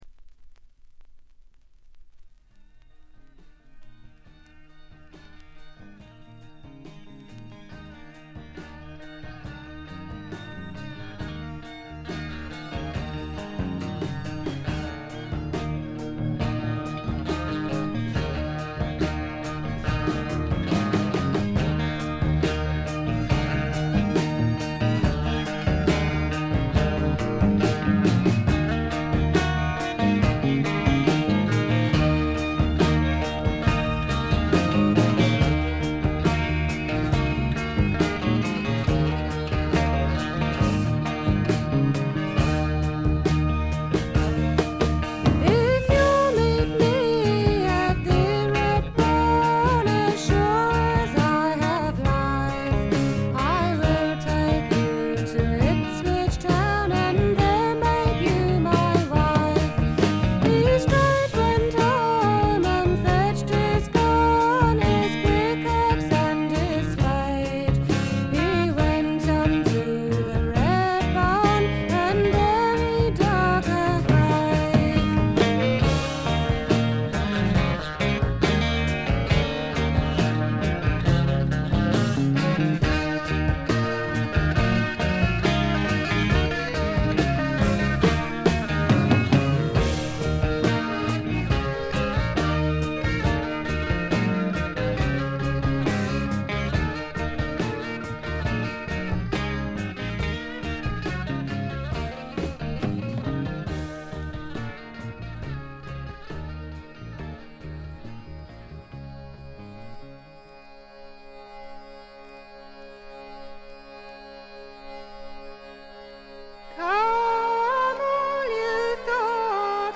ごくわずかなノイズ感のみ。
エレクトリック・トラッド最高峰の一枚。
試聴曲は現品からの取り込み音源です。